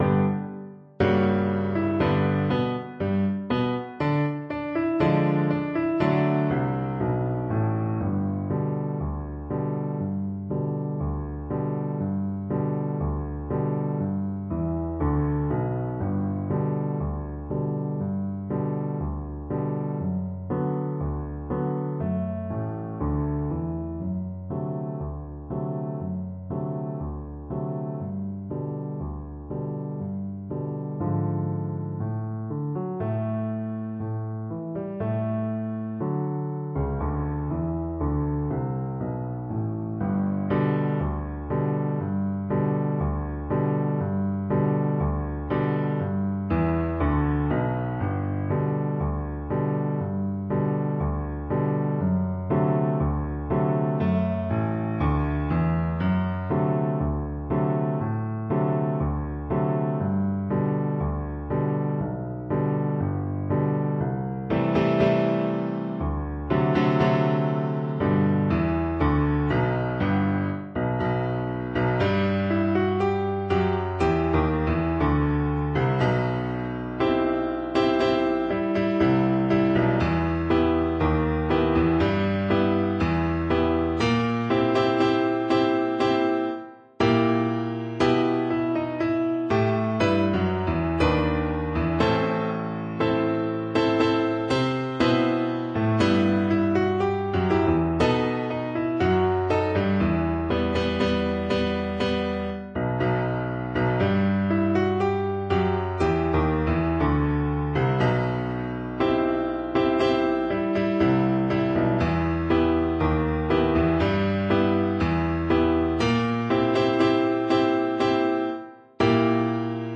Classical Cello
2/2 (View more 2/2 Music)
Quick March = c.104